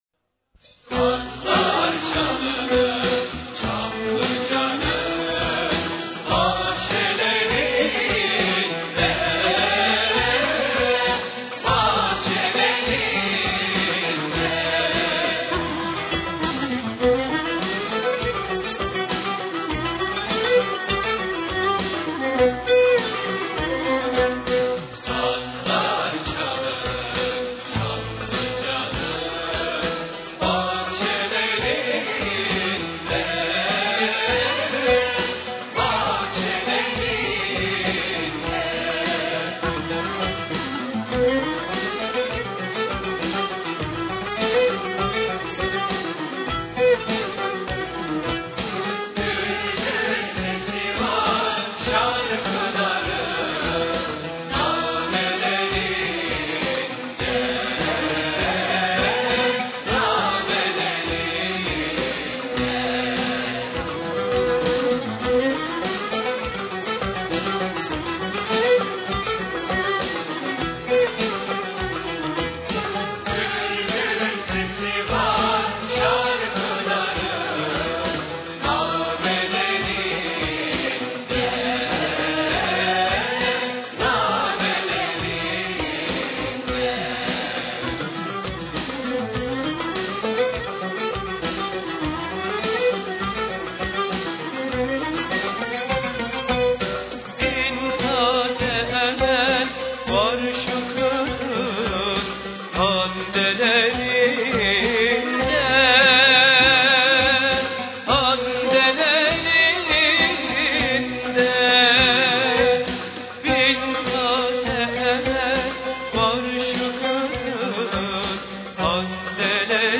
1. makam: hicâz
usul: düyek